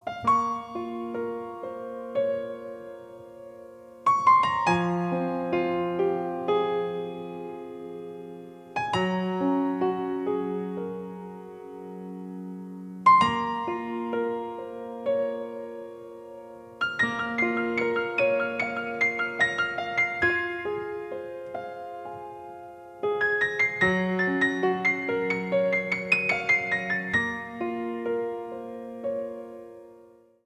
Поп Музыка # грустные # спокойные # без слов